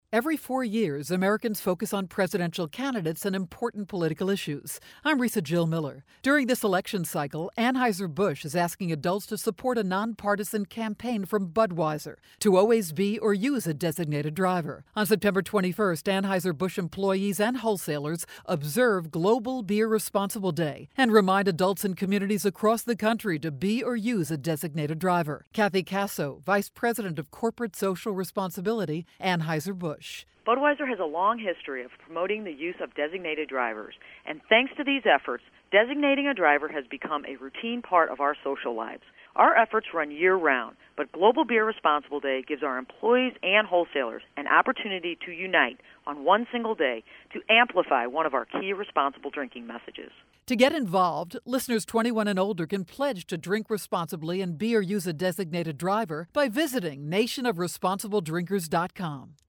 September 26, 2012Posted in: Audio News Release